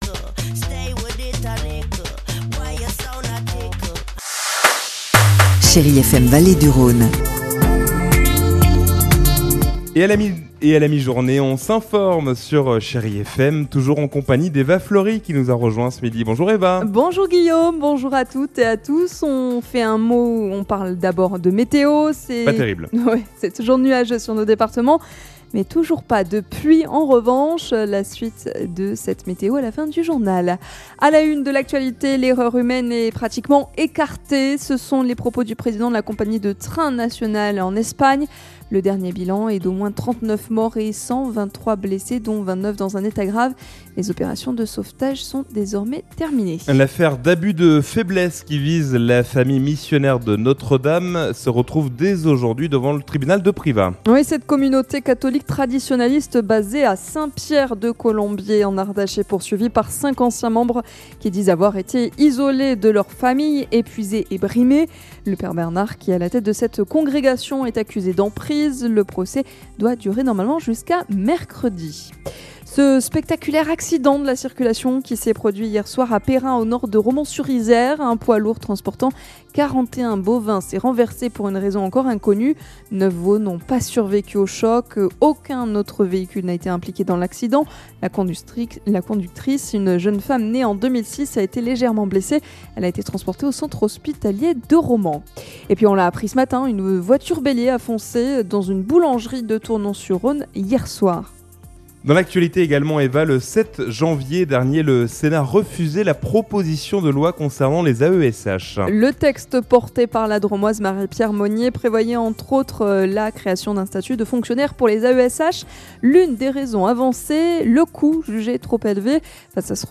Lundi 19 janvier : Le journal de 12h